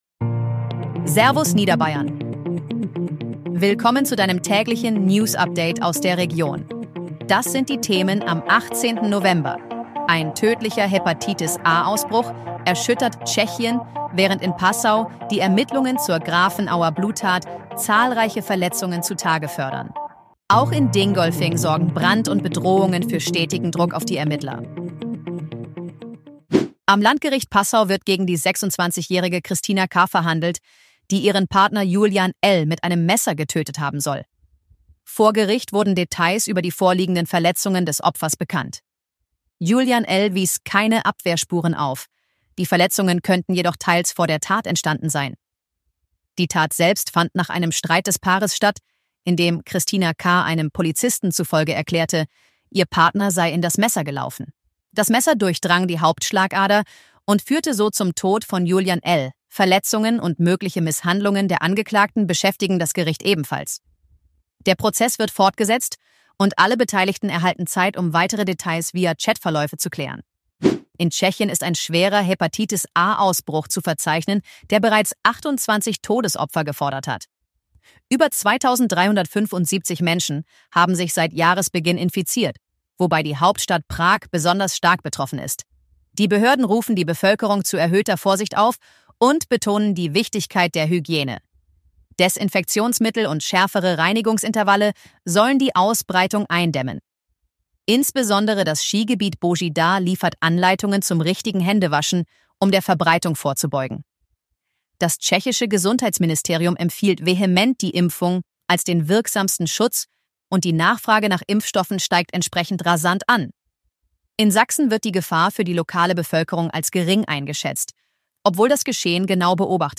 Dein tägliches News-Update